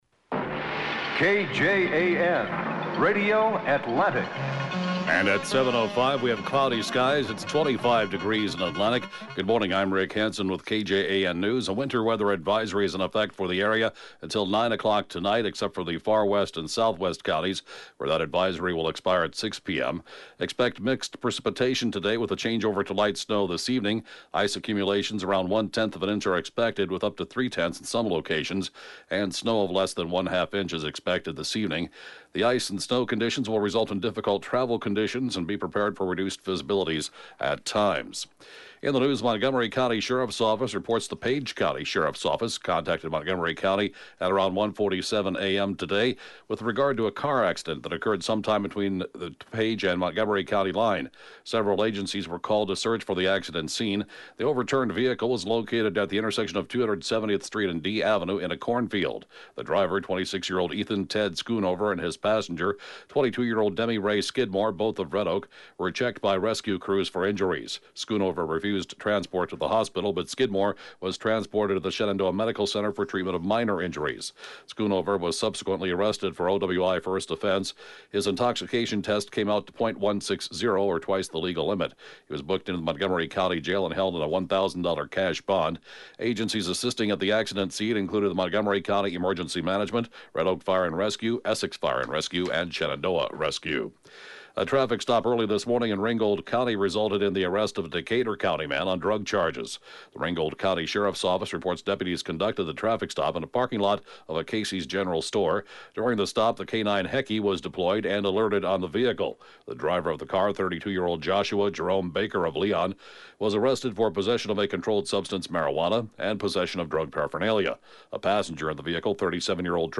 (Podcast) KJAN Morning News & funeral report, 2/24/2018